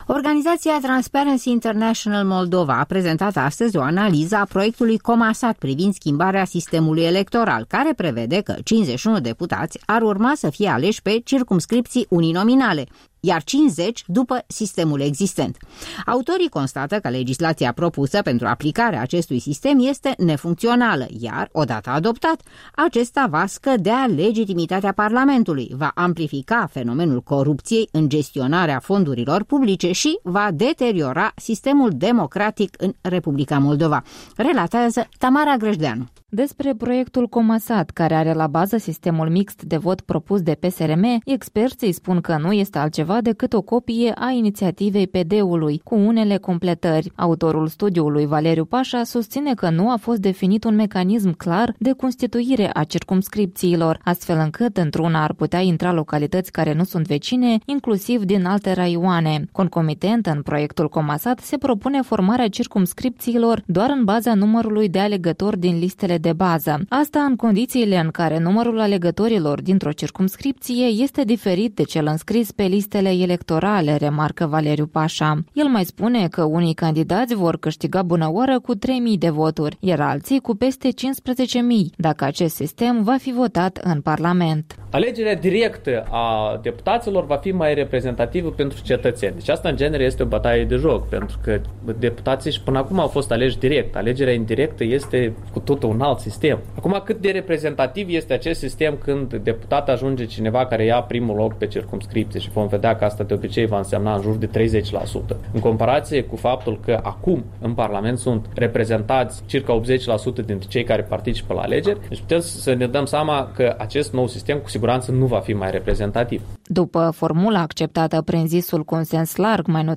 O dezbatere deschisă la Transparency International Moldova